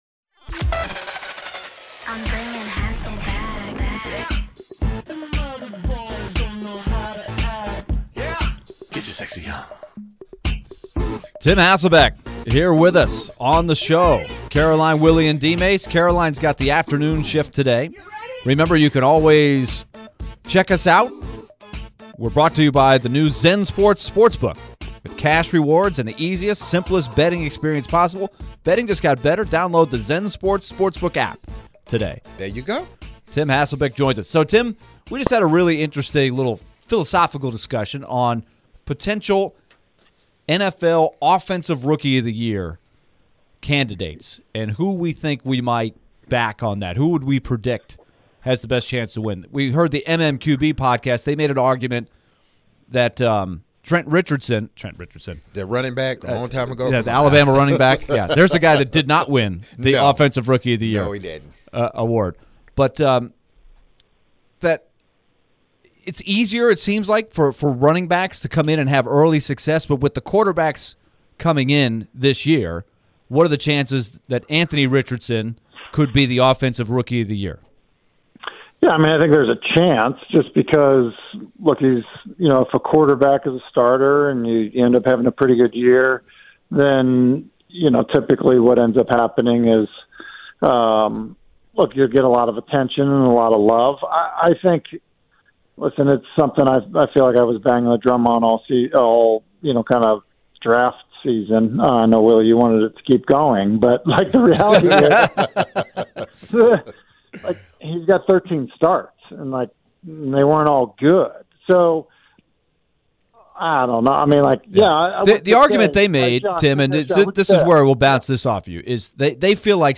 ESPN NFL Analyst Tim Hasselbeck joined for his weekly visit to discuss emerging rookie stars, gambling in the NFL and more.